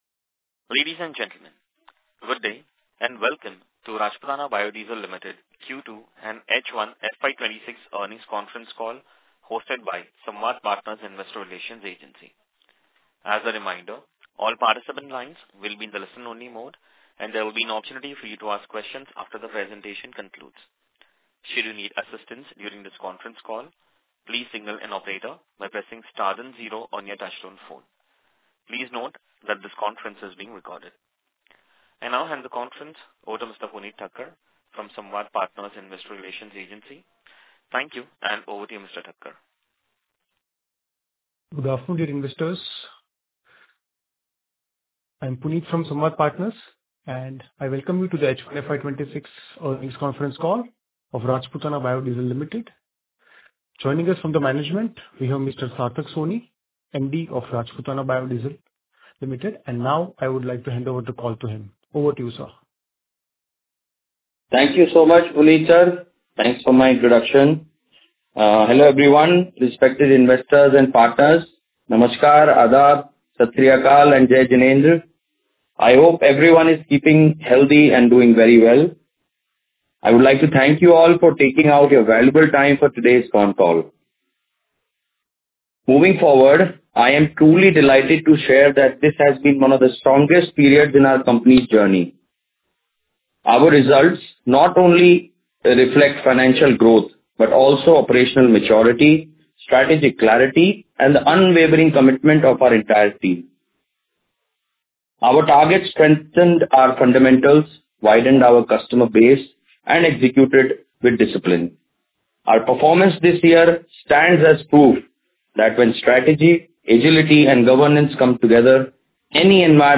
Earning calls Recordings H1 FY26
Earning-calls_Recordings-H1-FY26.mp3